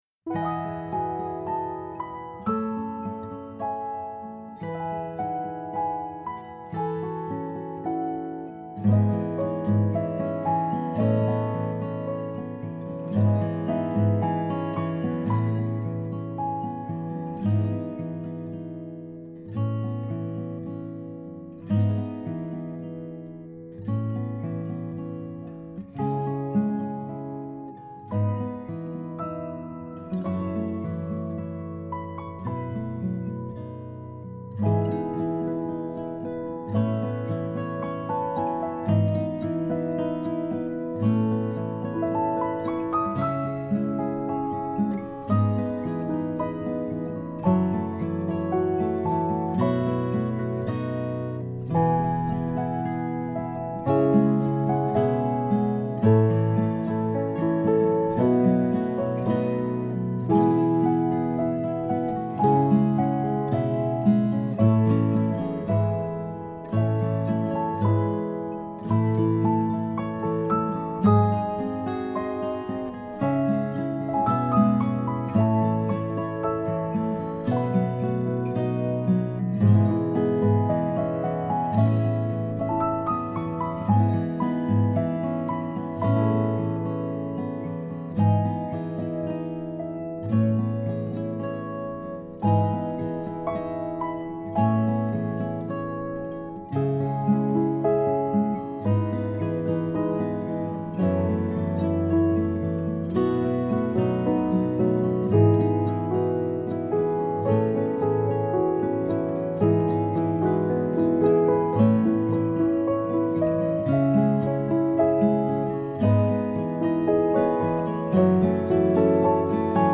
More Than You Know Piano & Guitar 2020
more-than-you-know-piano-guitar-7-6-20-n.mp3